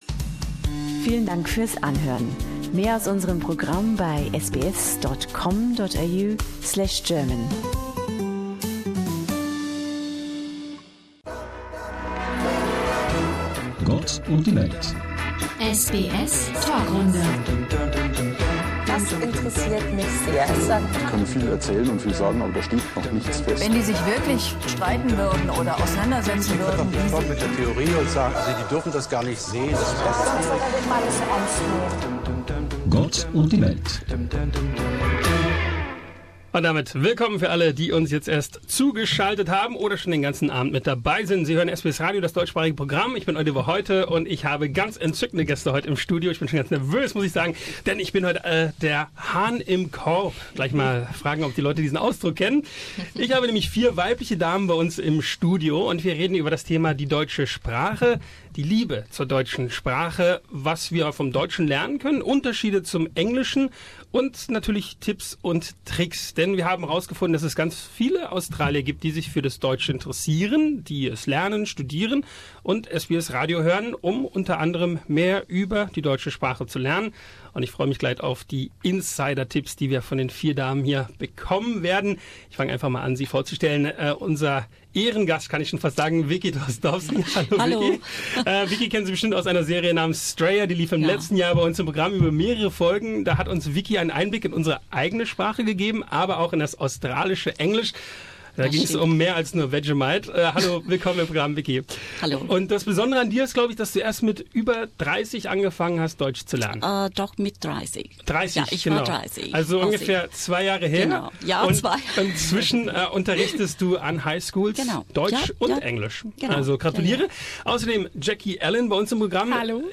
Unsere australischen Studiogäste beweisen, dass Mark Twain Unrecht hatte.